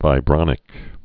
(vī-brŏnĭk)